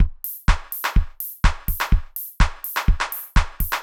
IBI Beat - Mix 8.wav